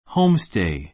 homestay hóumstei ホ ウ ムス テイ 名詞 ホームステイ ⦣ 留学生が家庭的雰囲気 ふんいき の中で勉強するために現地の一般 いっぱん 家庭に滞在 たいざい すること.